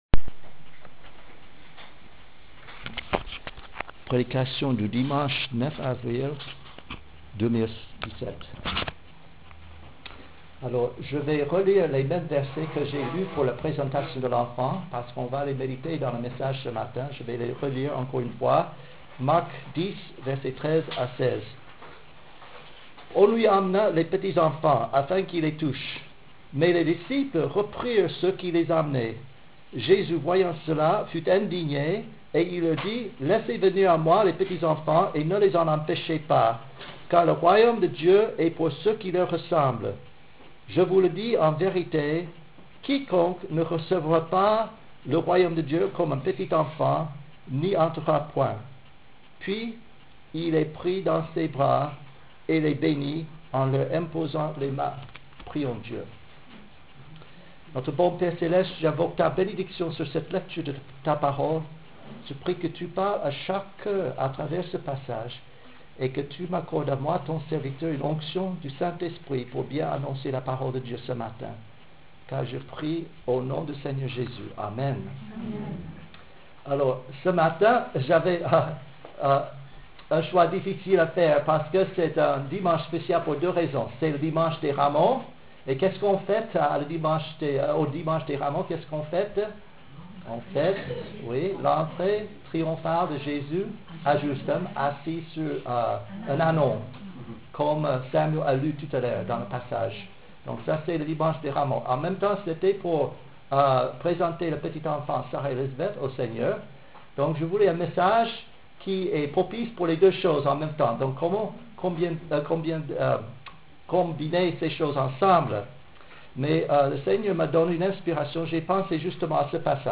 MESSAGE DU CULTE DU DIMANCHE 9 AVRIL 2017